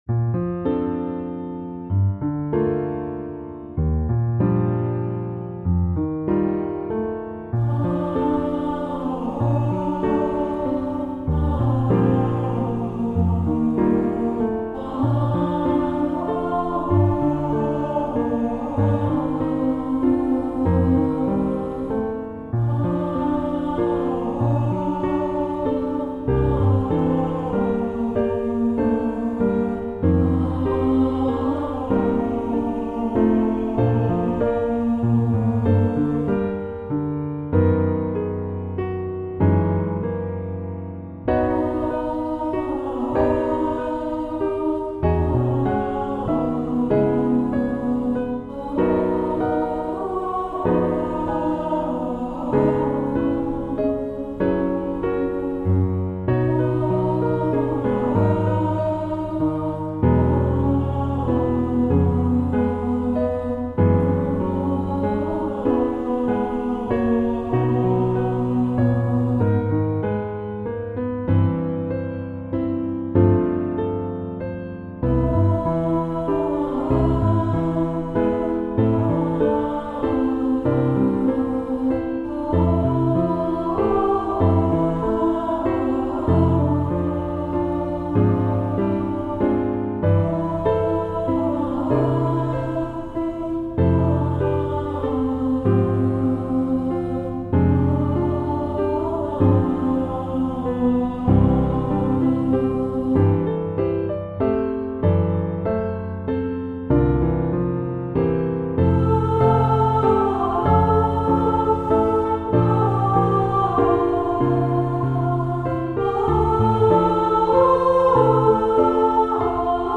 Vocal Solo Medium Voice/Low Voice